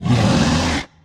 Minecraft Version Minecraft Version 1.21.5 Latest Release | Latest Snapshot 1.21.5 / assets / minecraft / sounds / mob / polarbear / warning2.ogg Compare With Compare With Latest Release | Latest Snapshot